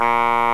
Index of /m8-backup/M8/Samples/Fairlight CMI/IIX/REEDS
BASSOONE.WAV